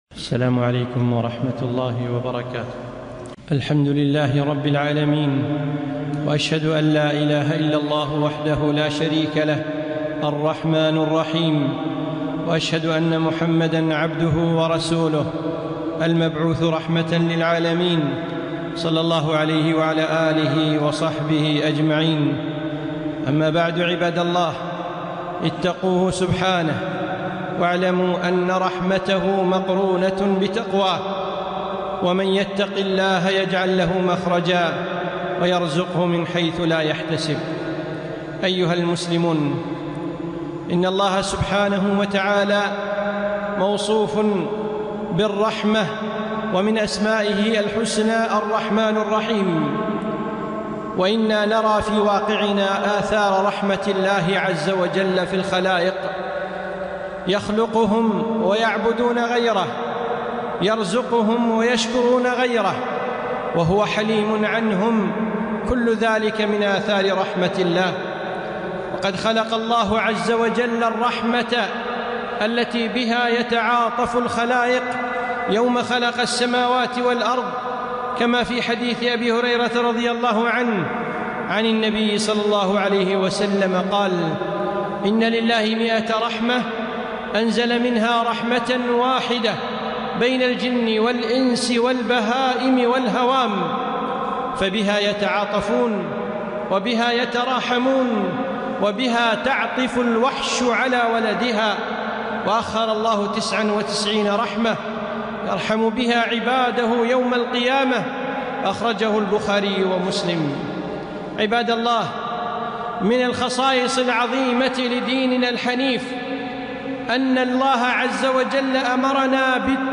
خطبة - ارحموا ترحموا